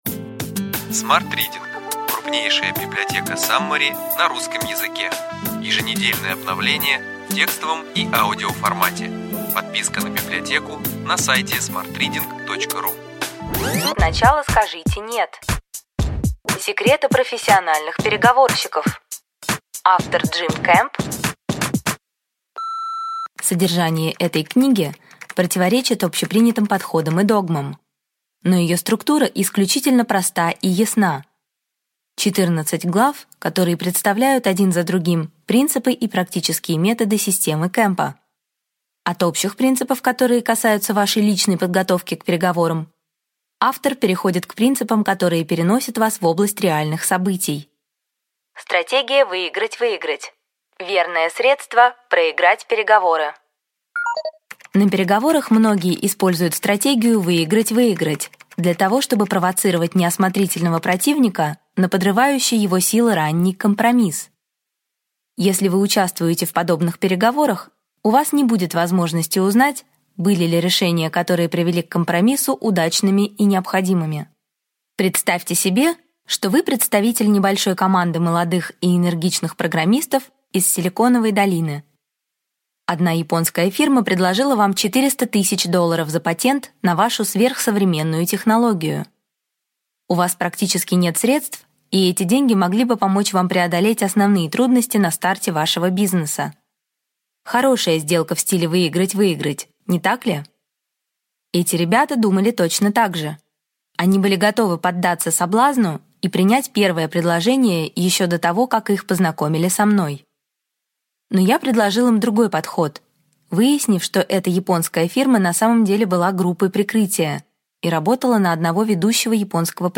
Аудиокнига Ключевые идеи книги: Сначала скажите «нет». Секреты профессиональных переговорщиков.